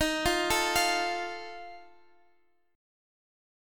D#sus2b5 Chord